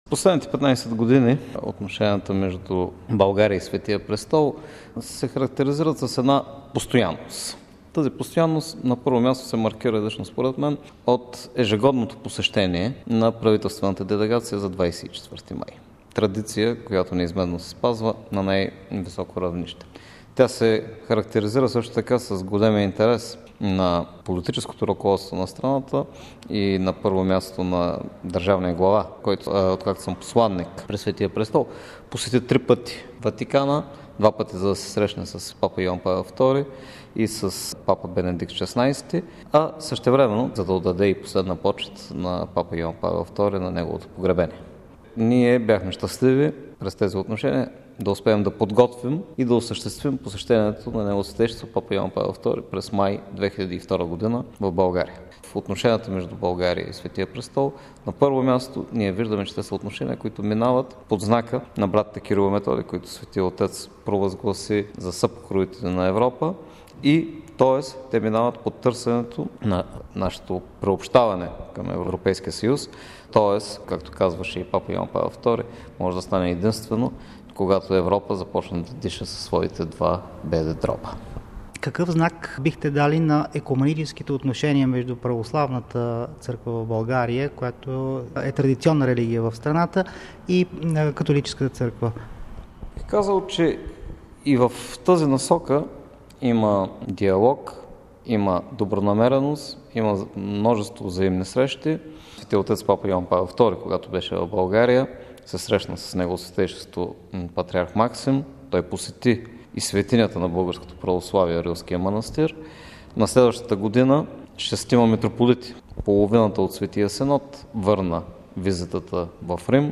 В каква посока се развиват и с какво се характеризират досега? Отговор потърсихме от настоящия посланик г-н Владимир Градев.